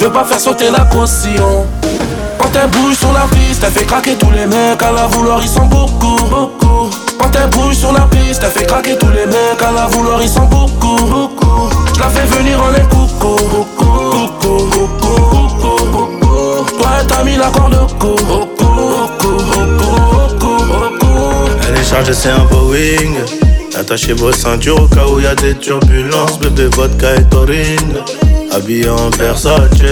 Жанр: Рэп и хип-хоп / Иностранный рэп и хип-хоп